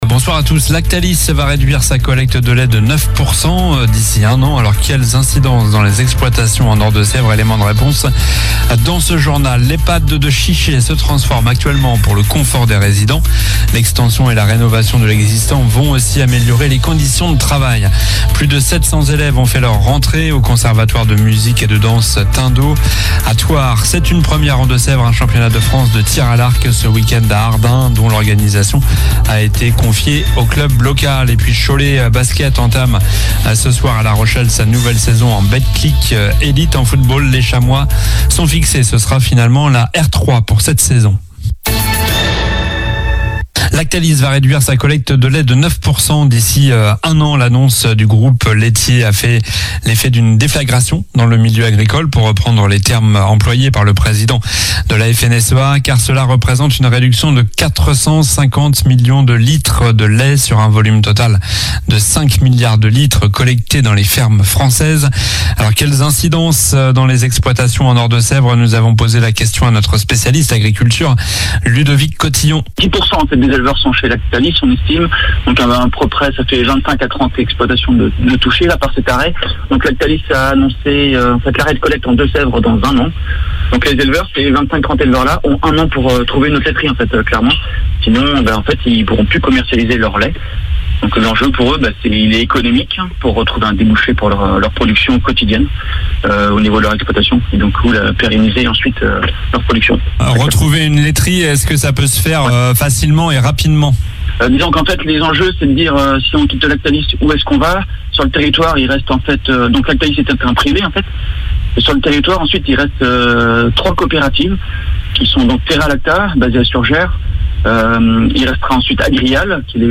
Journal du vendredi 27 septembre (soir)